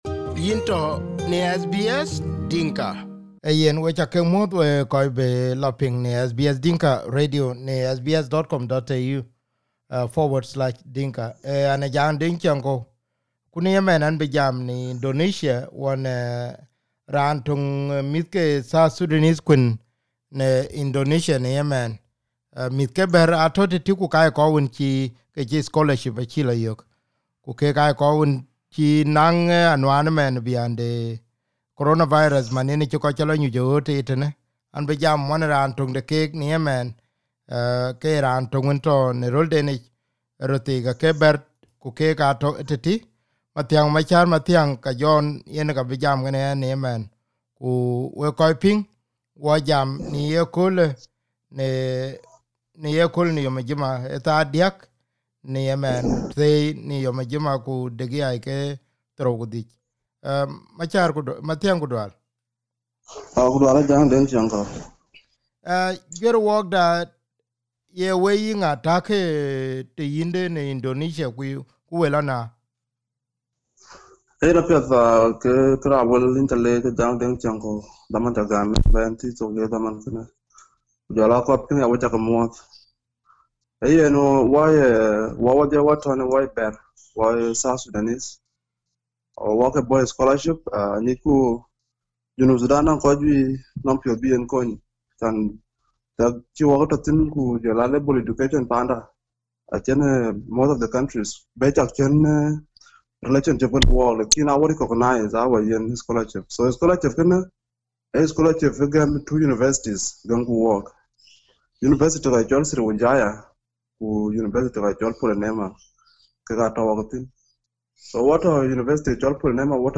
SBS Dinka radio interviewed